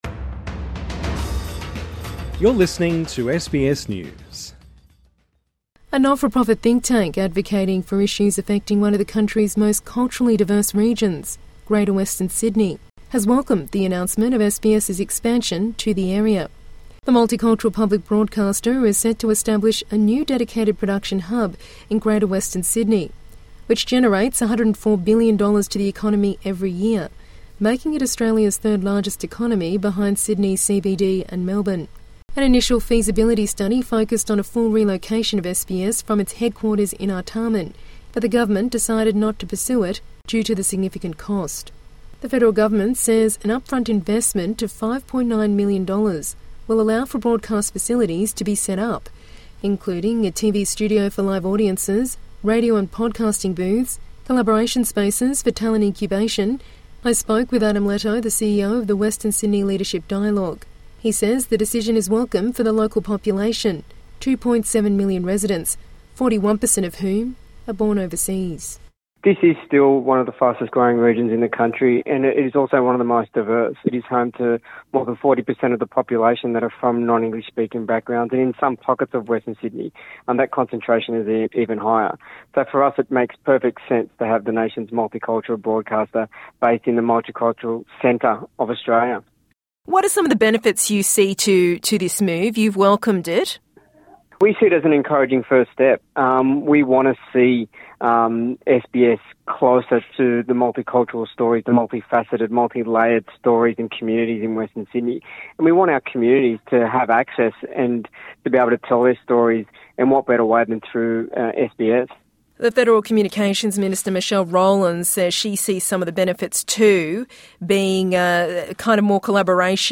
INTERVIEW: Permanent hub for SBS in Western Sydney welcomed for chance to share more untold stories